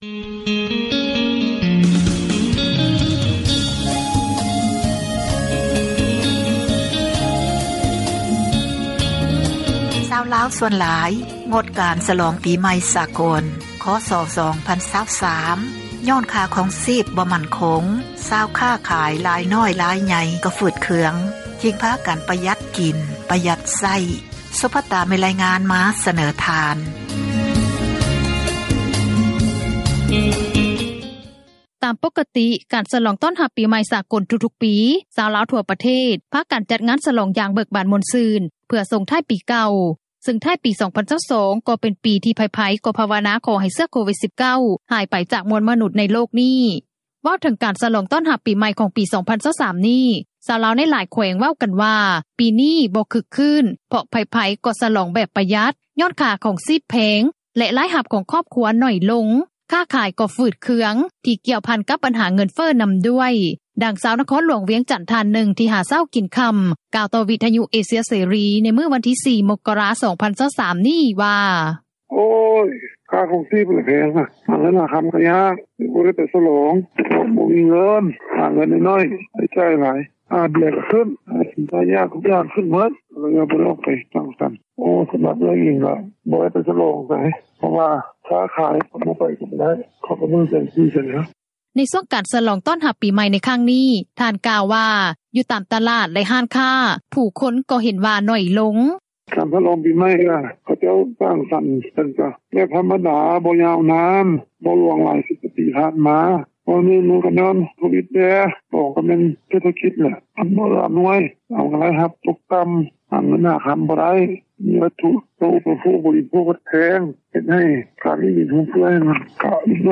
ດັ່ງ ຊາວນະຄອນຫຼວງຈັນ ທ່ານນຶ່ງ ທີ່ຫາເຊົ້າກິນຄໍ່າ ກ່າວຕໍ່ວິທຍຸ ເອເຊັຽ ເສຣີ ໃນມື້ວັນທີ 4 ມົກກະຣາ 2023 ນີ້ວ່າ: